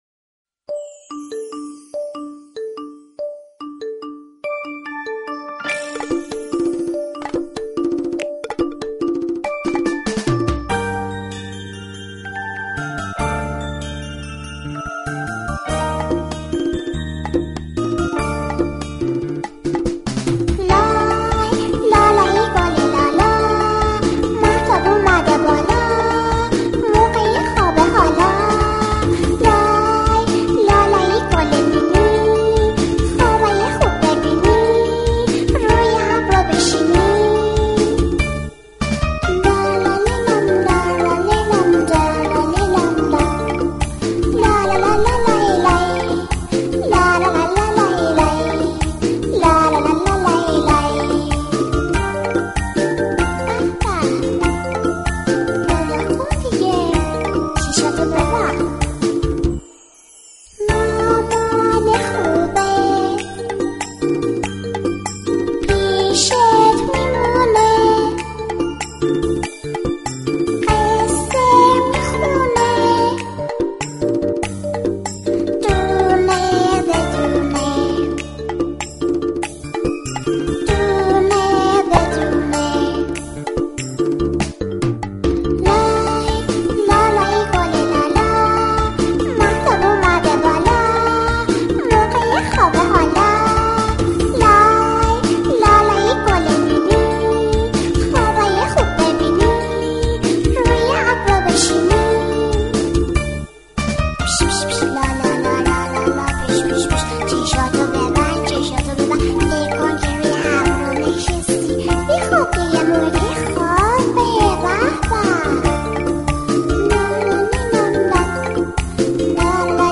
لالایی-گل-لالا.mp3